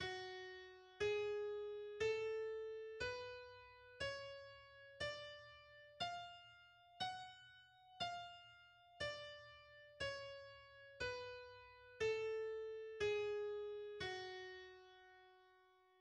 The F-sharp harmonic minor and melodic minor scales are: